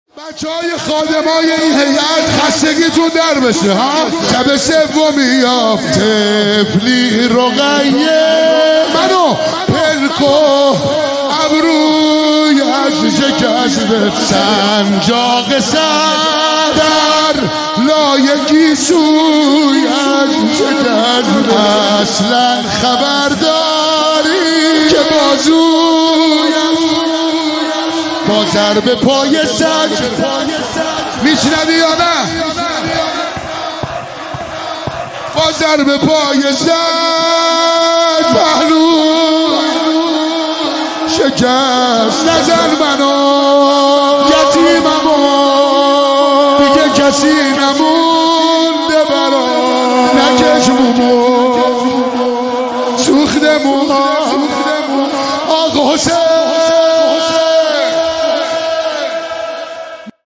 ولادت حضرت رقیه(س) هیئت جوانان سیدالشهدا(ع)تهران